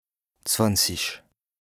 2APRESTA_OLCA_LEXIQUE_INDISPENSABLE_BAS_RHIN_101_0.mp3